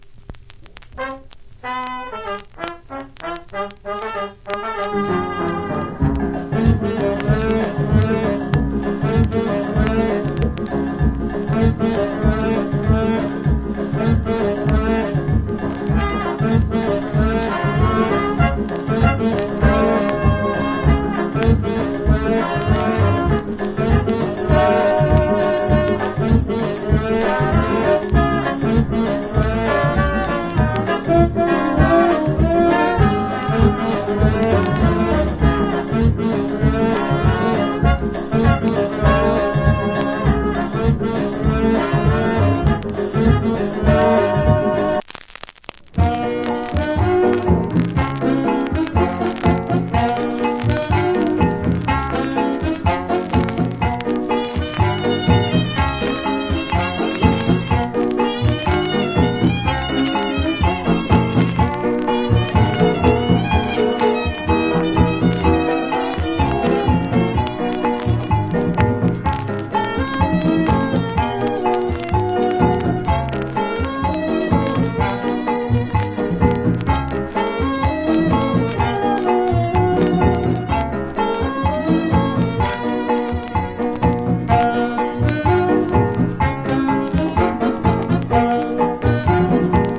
Cool Calypso Inst.